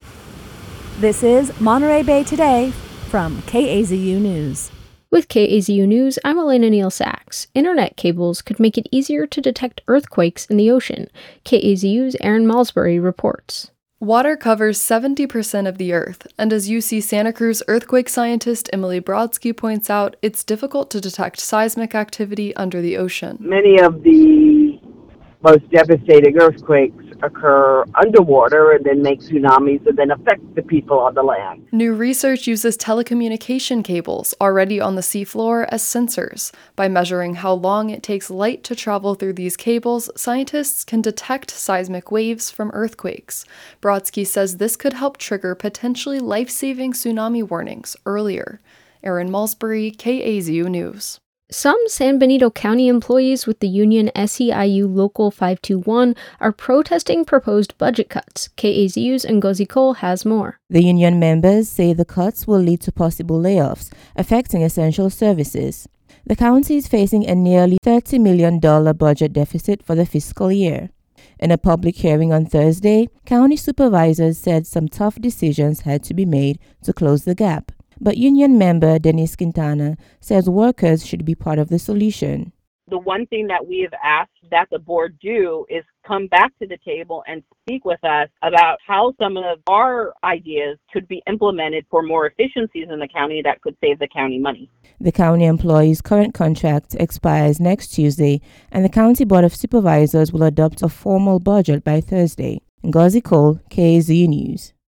sep-26-newscast-mixdown.mp3